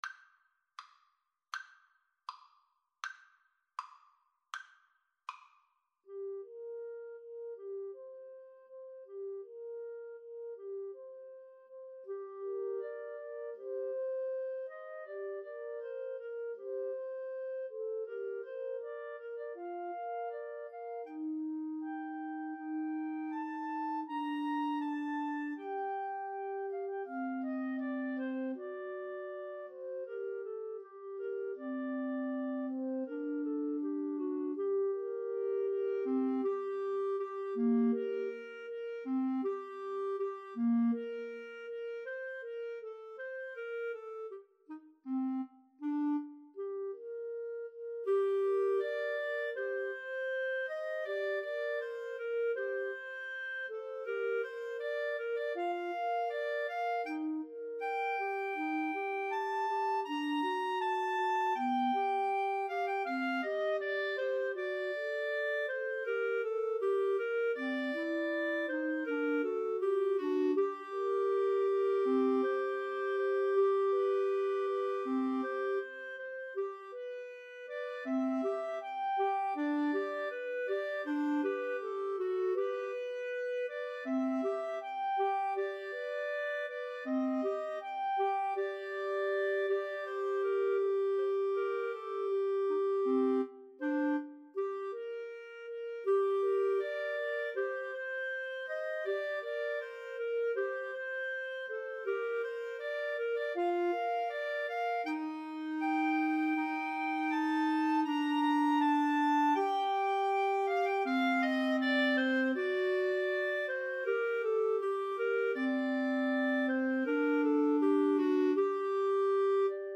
~ = 100 Andante
Classical (View more Classical Clarinet Trio Music)